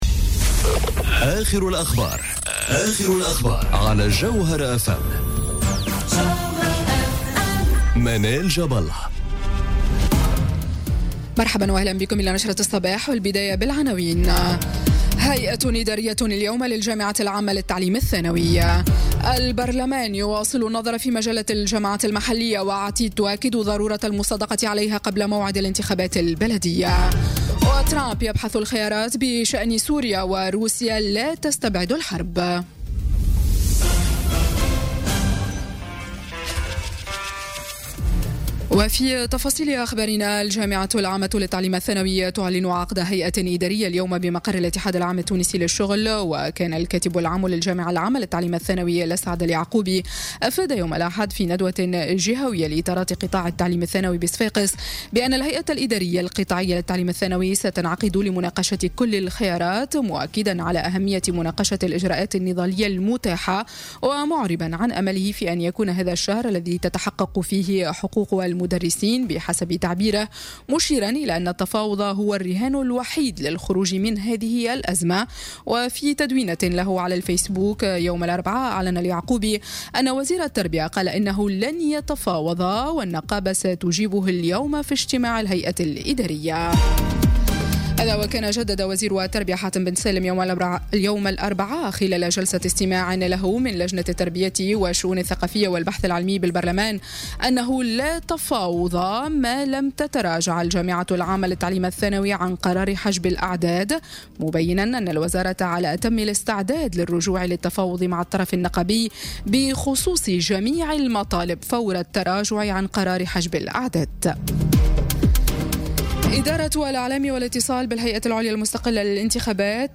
نشرة أخبار السابعة صباحا ليوم الجمعة 13 أفريل 2018